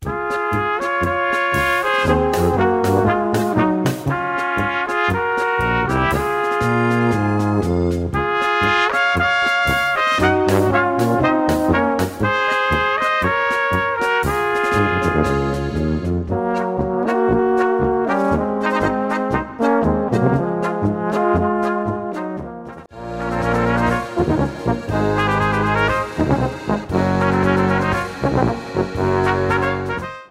Gattung: Polka für kleine Besetzung
Besetzung: Kleine Blasmusik-Besetzung